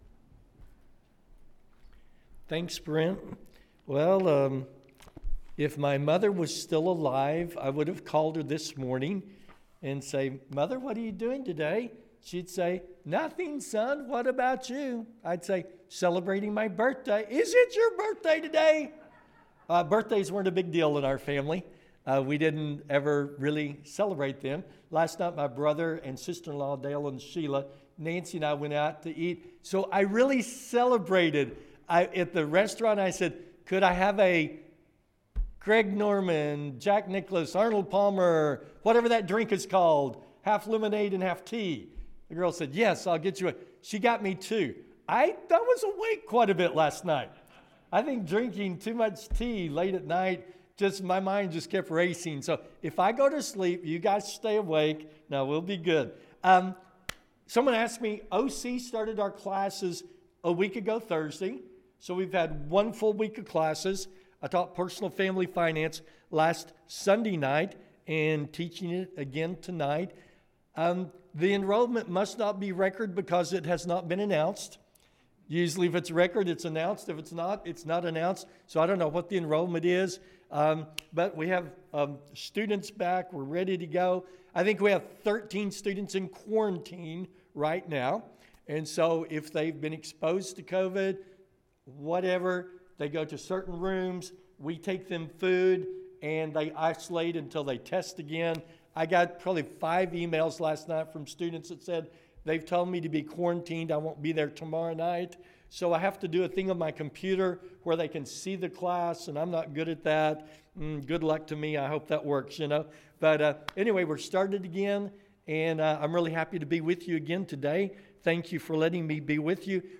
Using Your Talents for God – Sermon